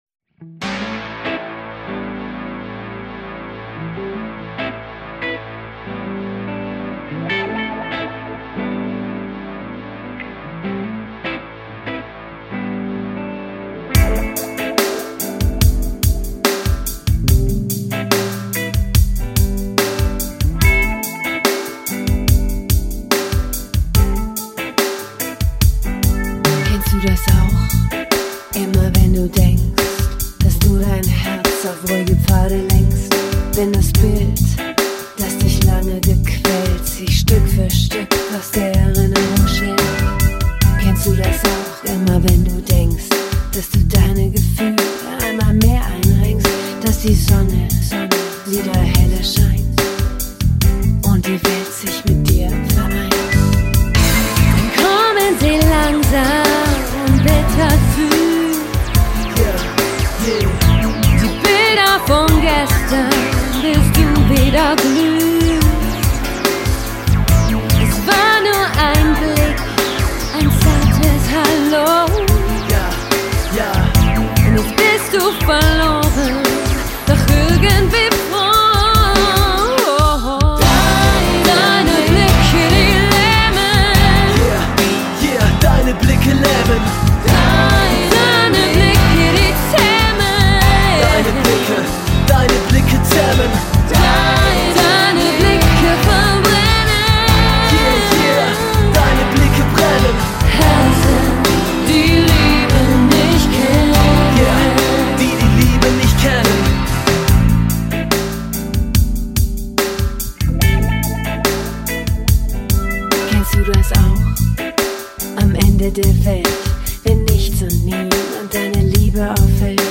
Genre: Kampagnen-Musik